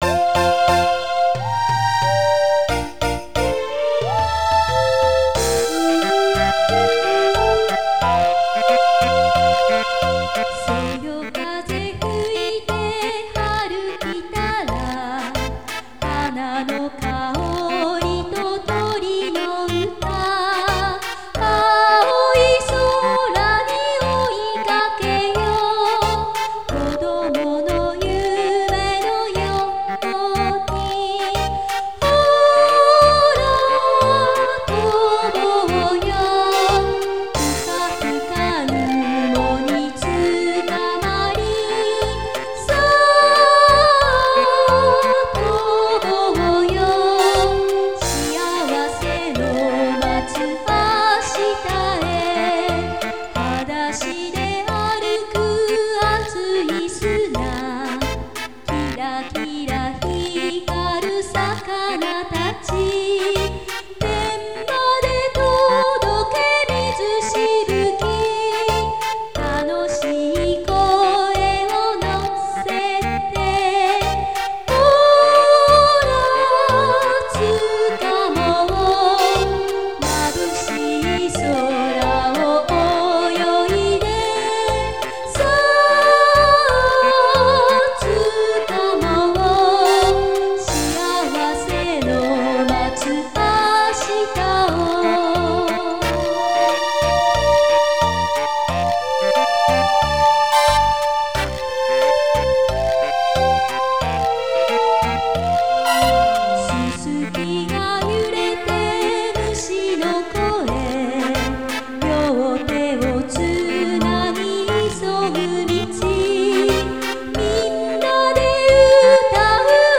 ふる里つむぎ歌詞：幼い頃の故郷（ふるさと）の四季を童謡風にまとめた歌です。